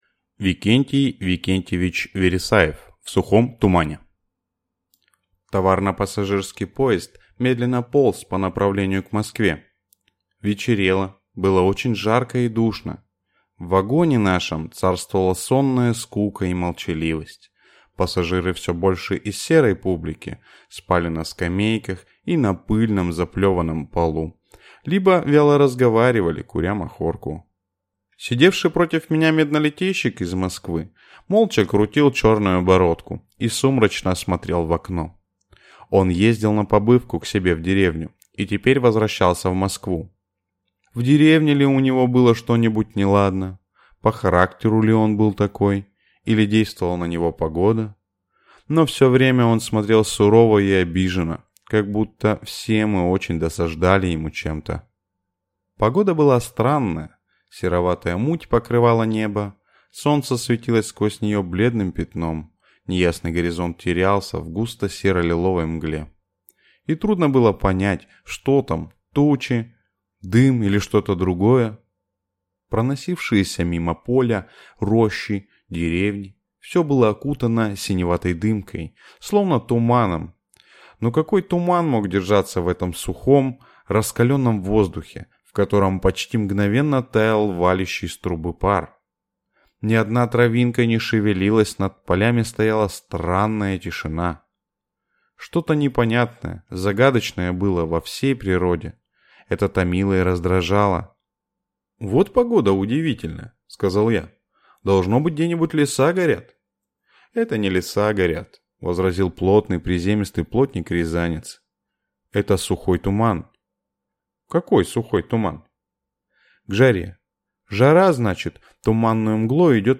Аудиокнига В сухом тумане | Библиотека аудиокниг